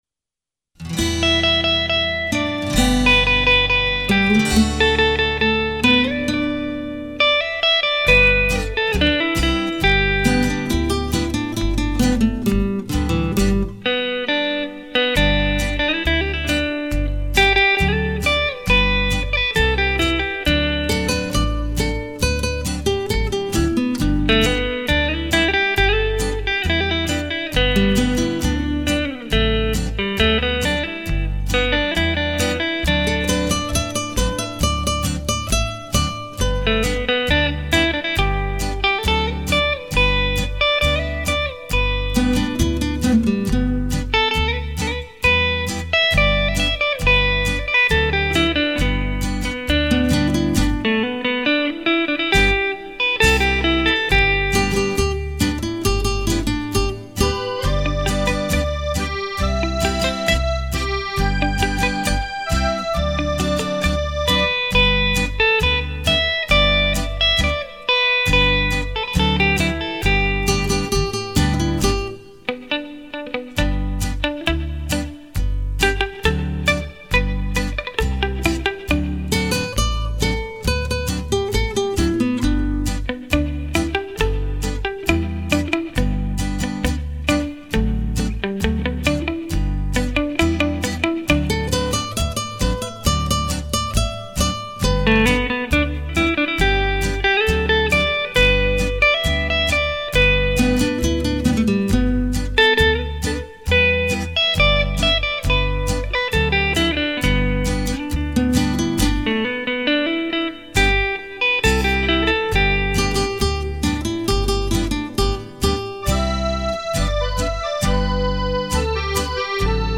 畅销东洋演歌名曲演奏